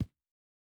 Shoe Step Stone Hard E.wav